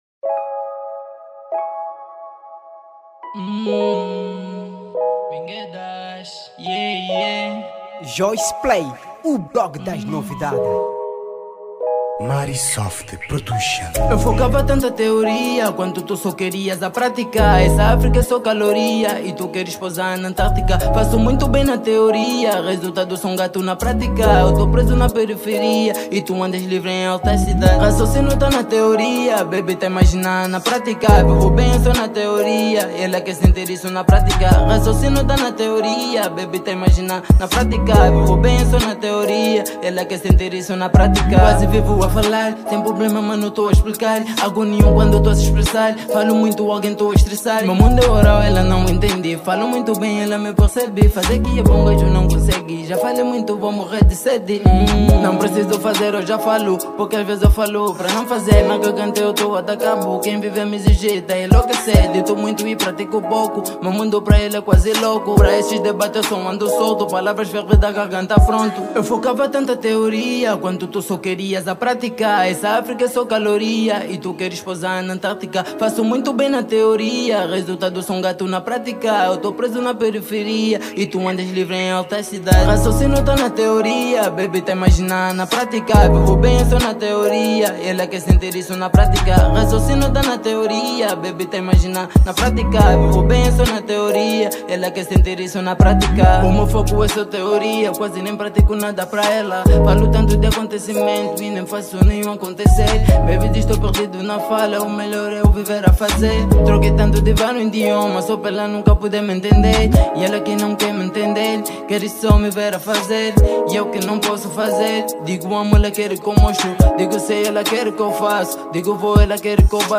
Categoria: Trap